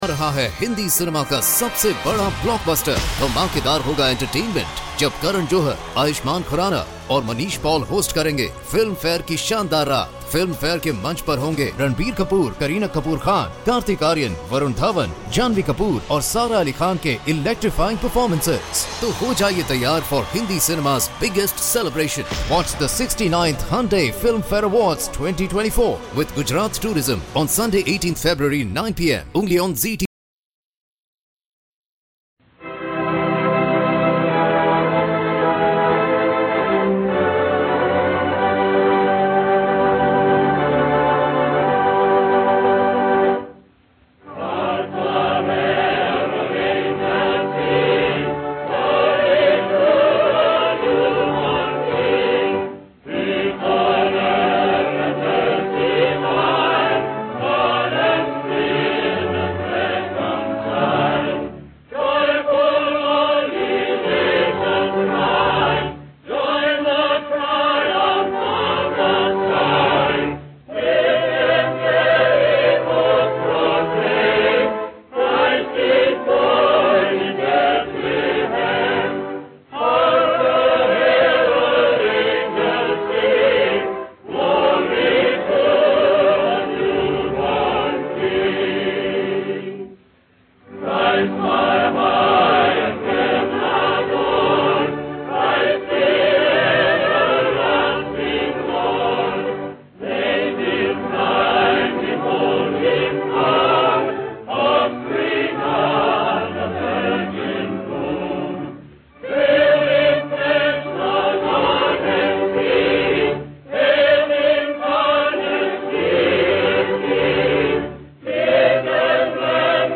OTR Christmas Shows - Chorus With Organ - As With Gladness Men Of Old & Hark The Herald Angels Sing - 1943-11-xx 050 V-Disc B